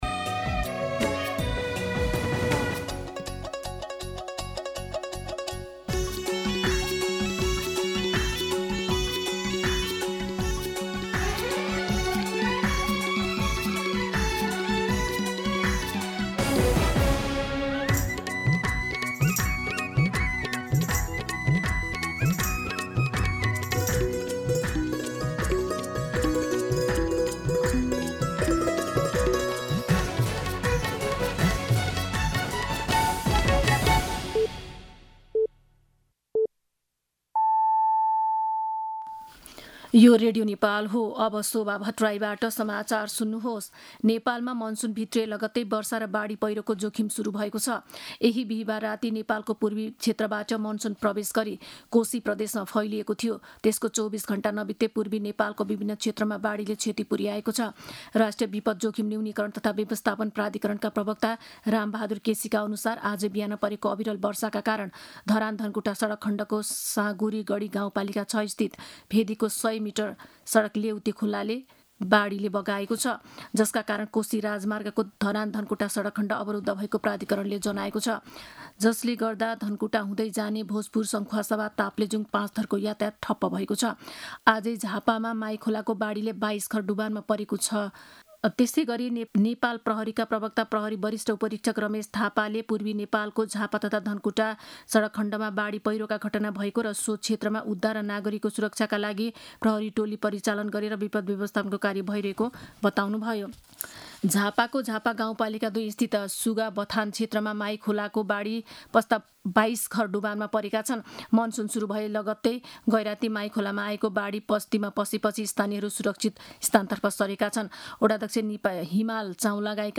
मध्यान्ह १२ बजेको नेपाली समाचार : १७ जेठ , २०८२
12-pm-Nepali-News-6.mp3